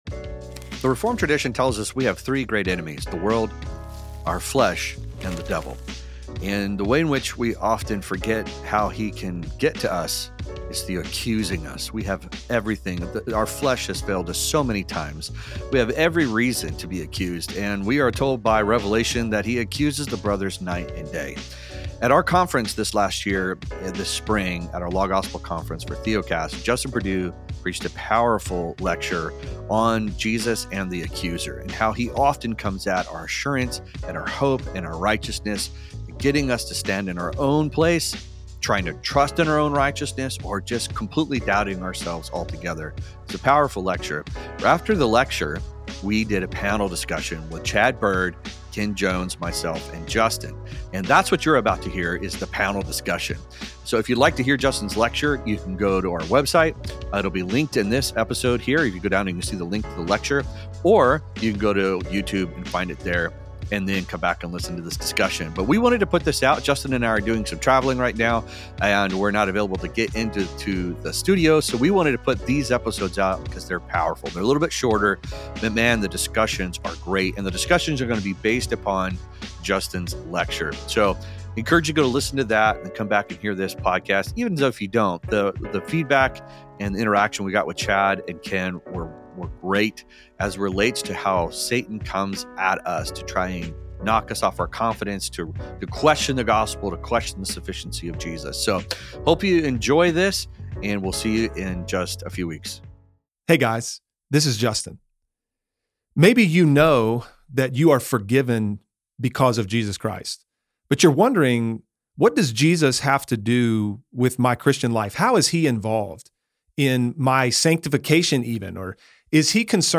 At our recent Law-Gospel conference
panel discussion